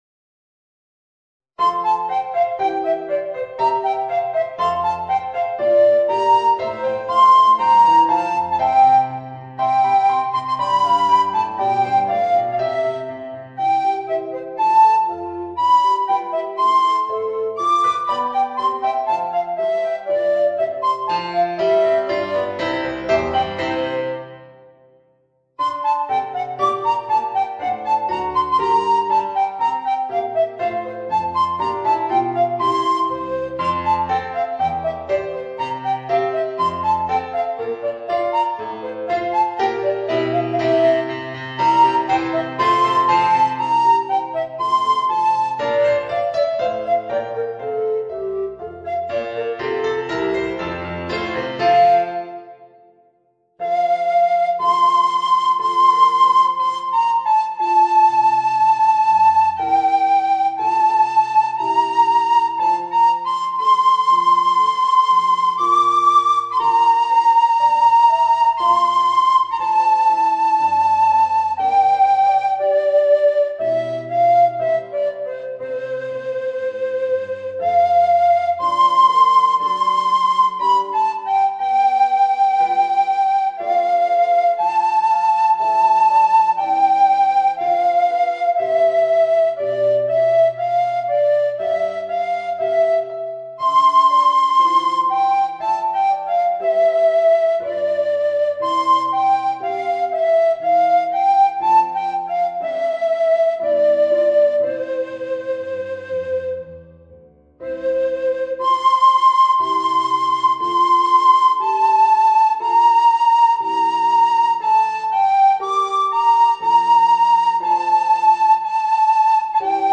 Tuba et Piano